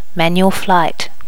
Normalize all wav files to the same volume level.
manual flight.wav